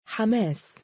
Hamas_pronunciation_ar
＊표준적인 악센트 위치는 「마」 부분